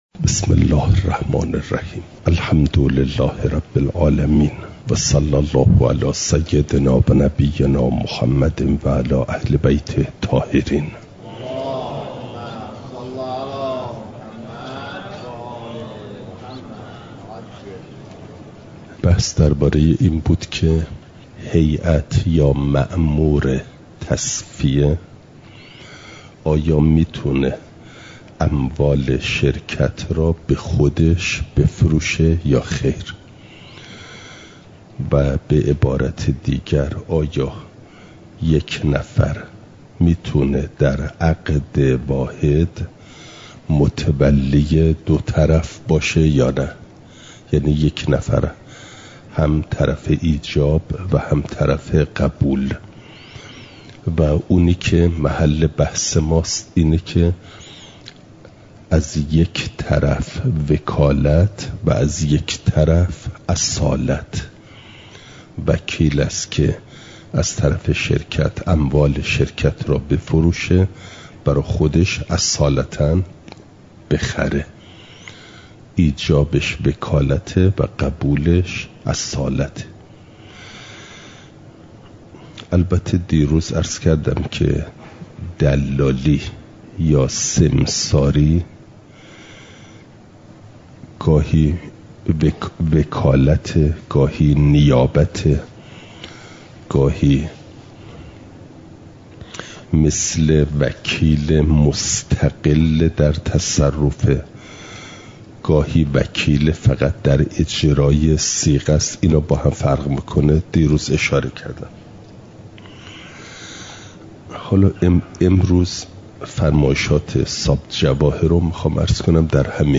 مسائل مستحدثه قضا (جلسه۹۶) – دروس استاد